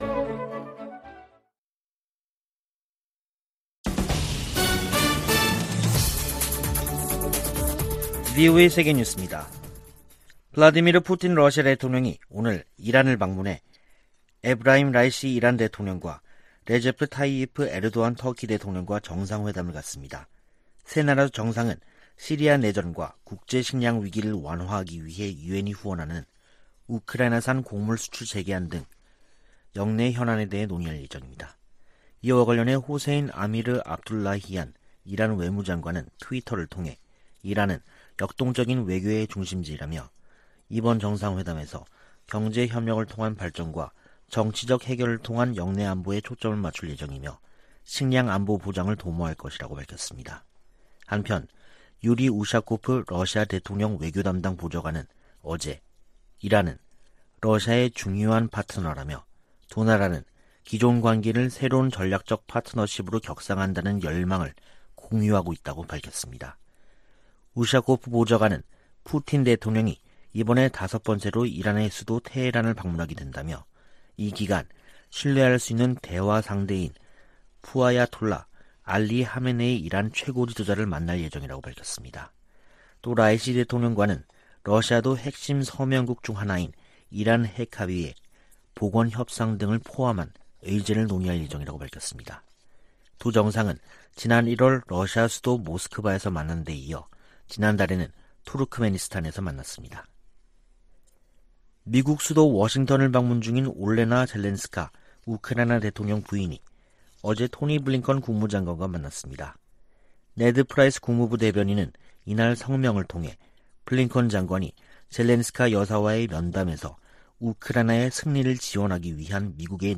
VOA 한국어 간판 뉴스 프로그램 '뉴스 투데이', 2022년 7월 19일 2부 방송입니다. 주한미군은 미한 공동 안보 이익을 방어하기 위해 필요하다는 입장을 미 국방부가 확인했습니다. 한국을 방문한 미 재무장관은 탄력성 있는 공급망 구축을 위한 협력을 강조하며 중국의 시장 지배적 지위를 막아야 한다고 말했습니다. 미 국무부가 북한의 인권 상황은 대량살상무기 만큼이나 우려스러운 부분이라고 지적했습니다.